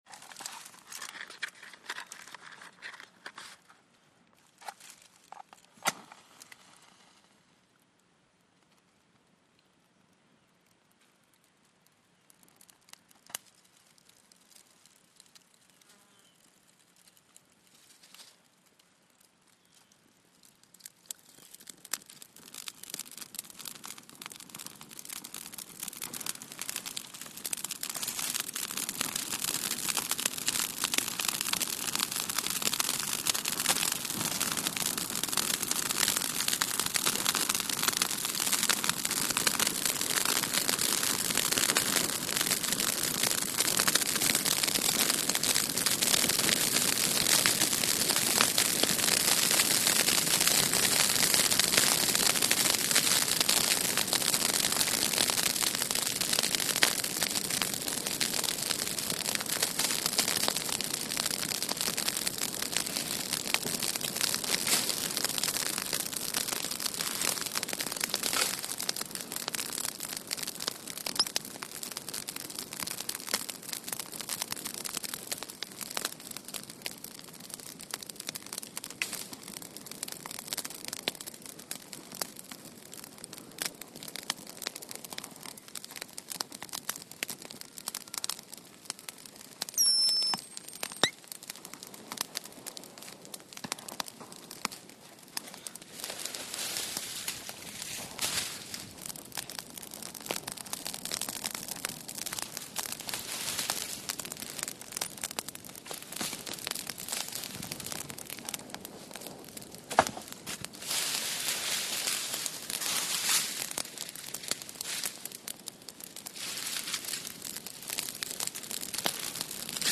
Campfire|Exterior
CampFireCrackly PE700401
FIRE CAMP FIRE: EXT: Lighting fire with a match, crackly fire, throwing brush in fire, close up, gas squeaks.. Fire Burn.